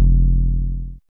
808s
Bass (3).wav